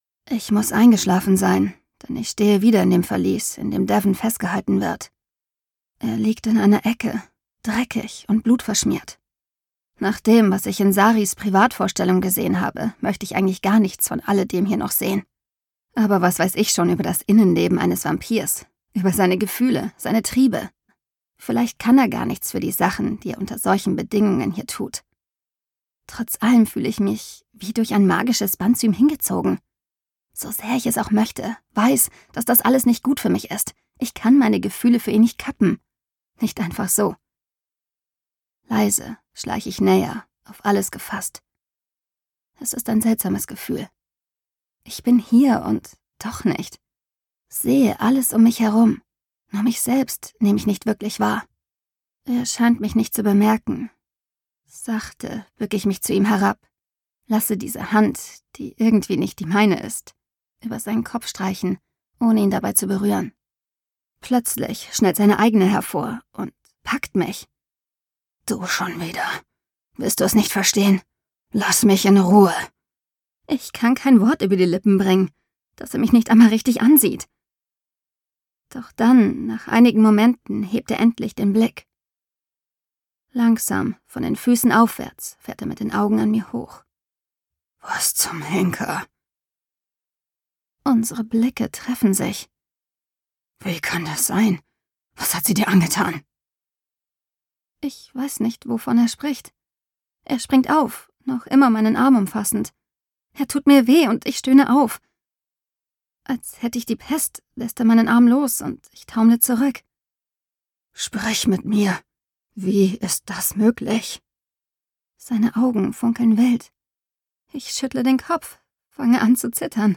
Stimmfarbe: dynamisch, hell, tief, kratzig, rau, jugendlich, erfrischend